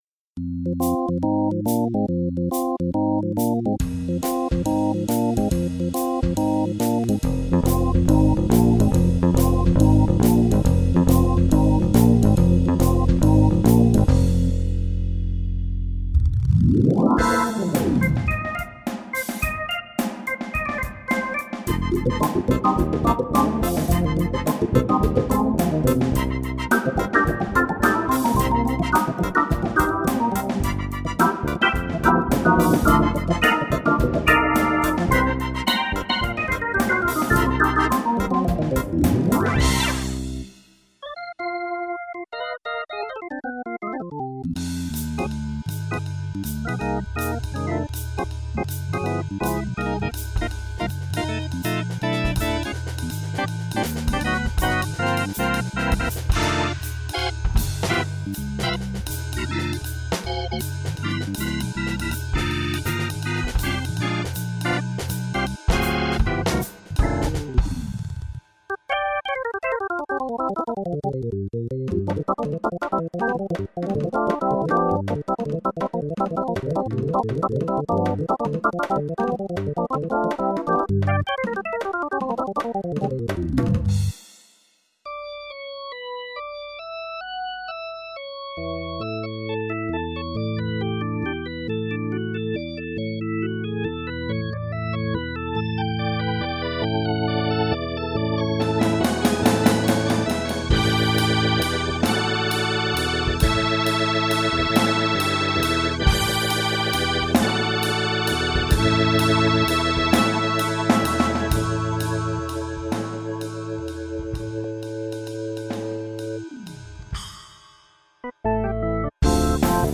オルガン